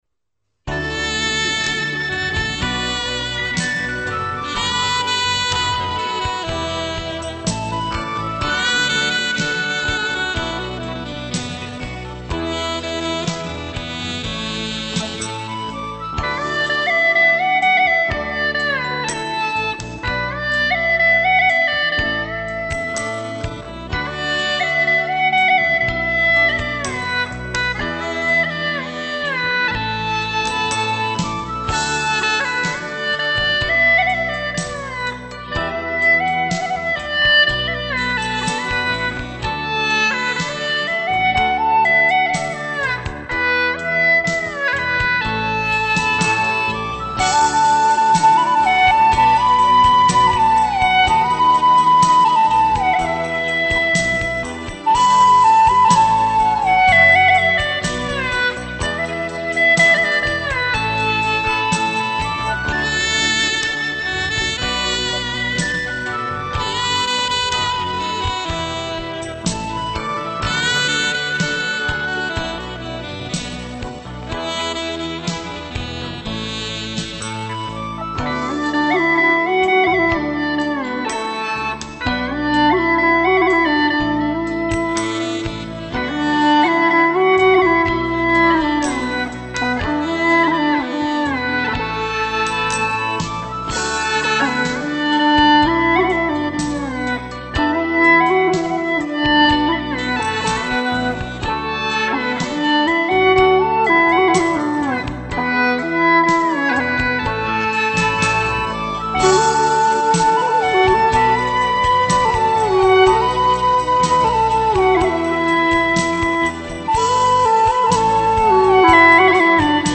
调式 : D